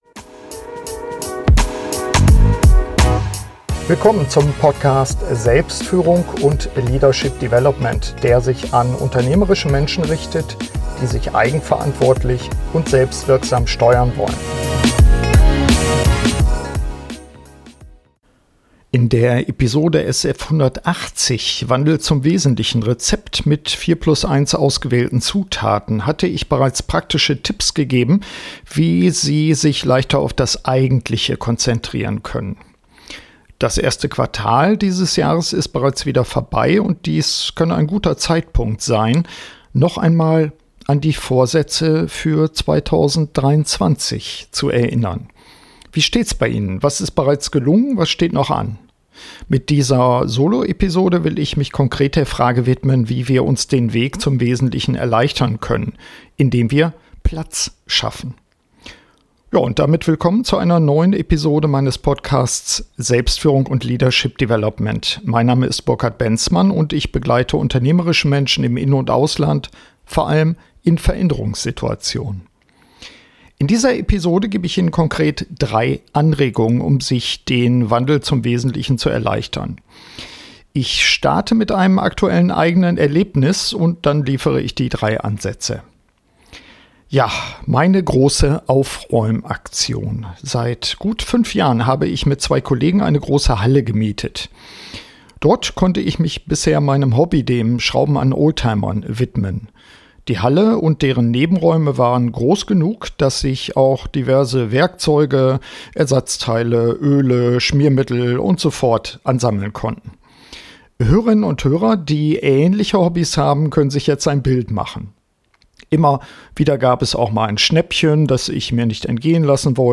Mit dieser Solo-Episode will ich mich konkret der Frage widmen, wie wir uns den Weg zum Wesentlichen erleichtern können - indem wir Platz schaffen. In dieser Episode gebe ich Ihnen drei Anregungen, um sich den Wandel zum Wesentlichen zu erleichtern.